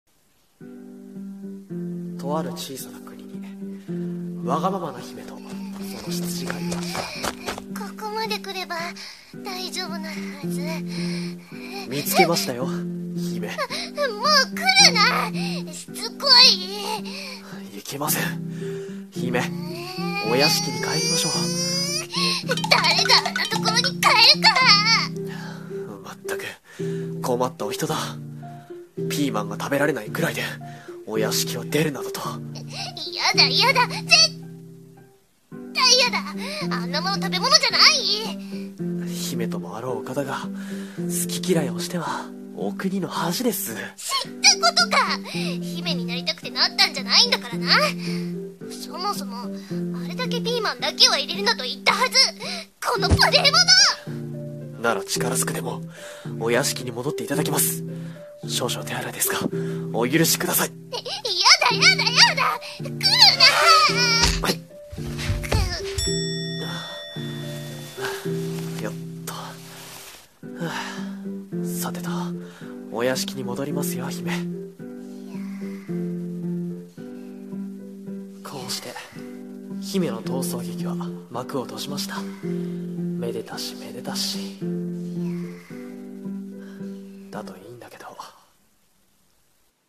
[台本] ワガママ姫と執事 2人用声劇